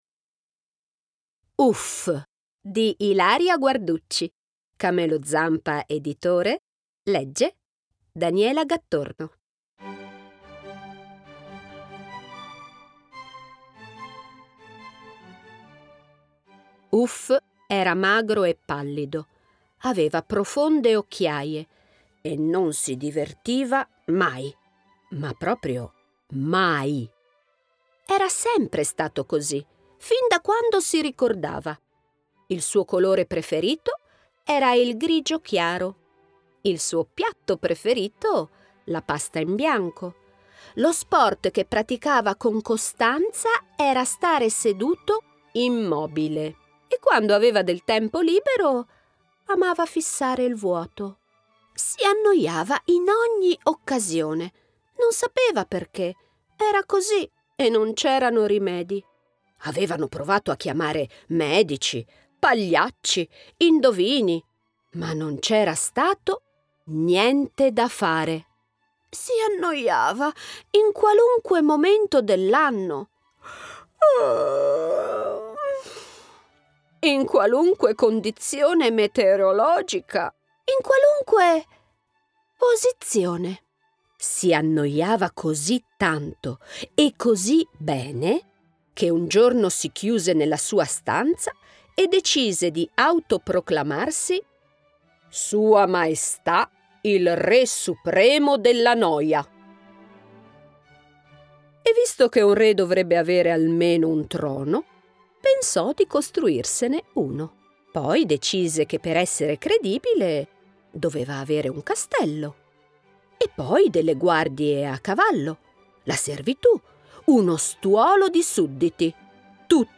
- Uff! - Audiolibro con tappeto sonoro